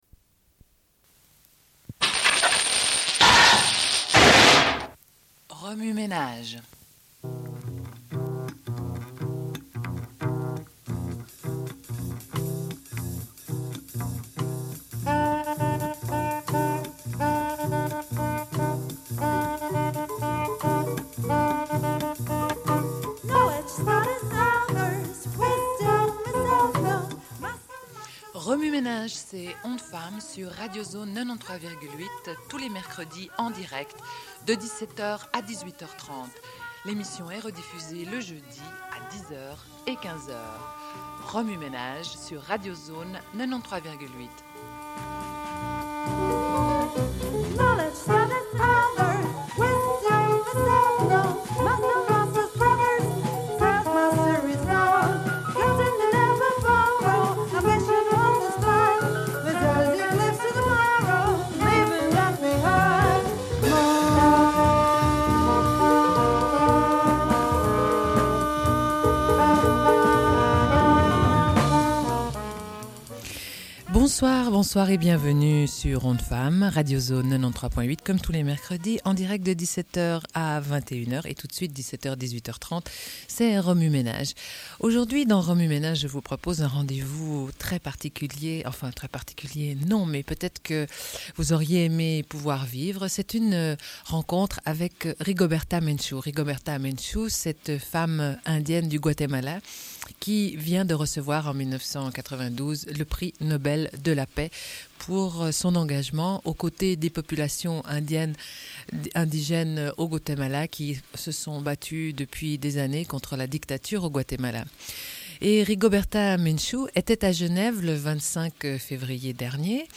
Une cassette audio, face A31:41